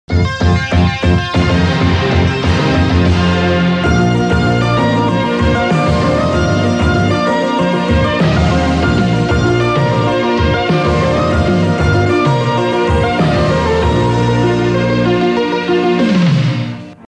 Sintonia del programa